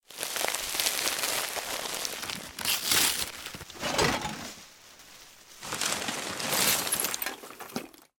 garbage_soft_1.ogg